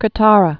(kə-tärə)